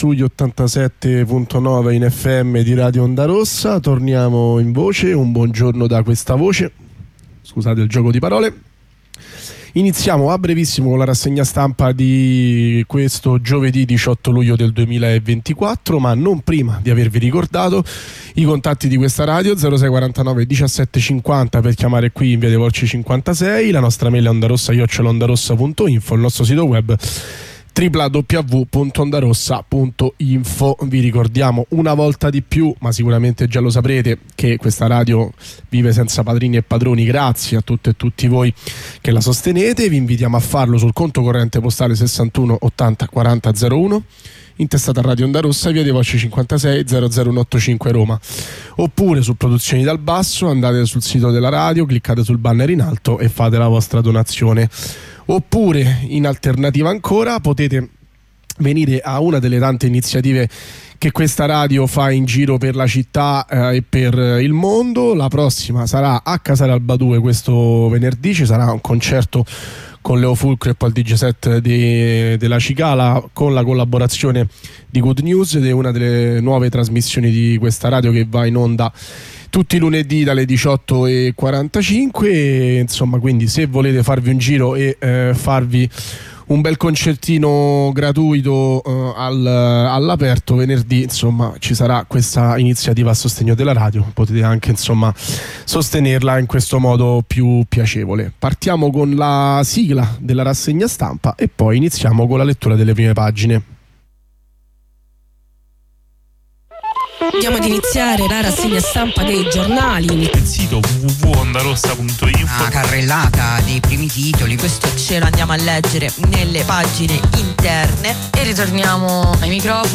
Rassegna stampa del 18 luglio 2024